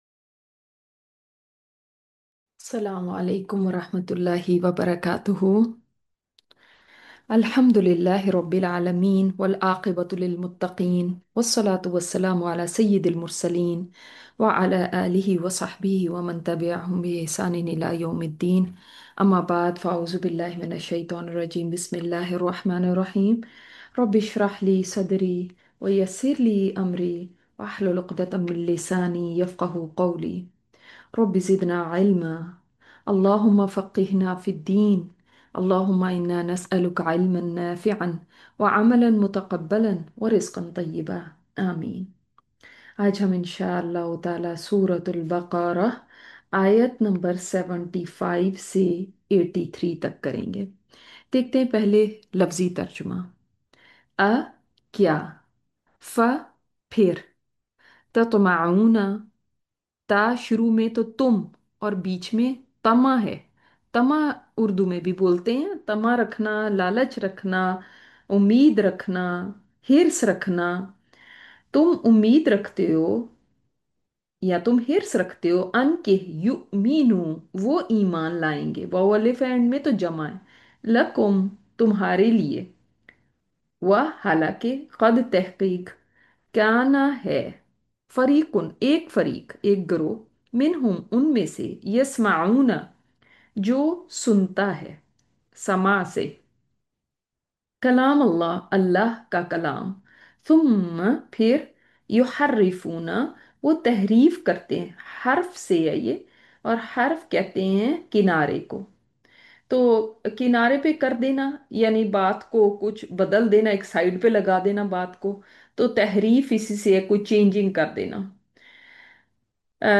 Latest Lecture